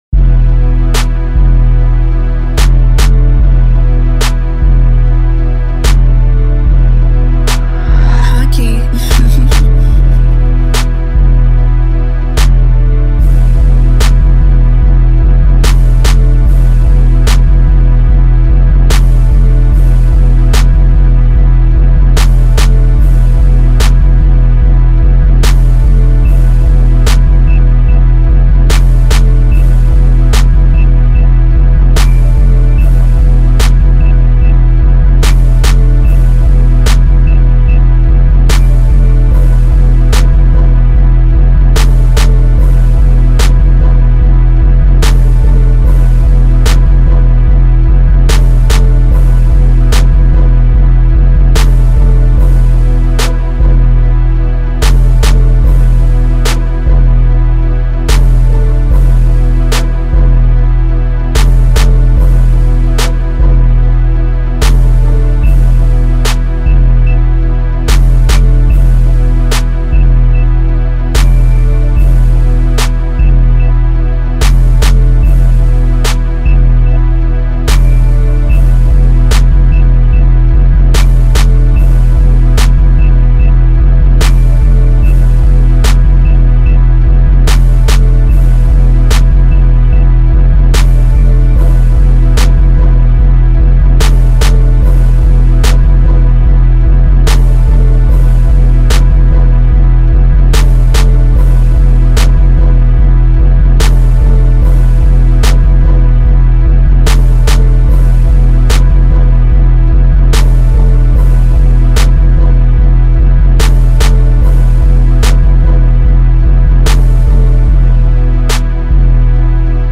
Trap Instrumental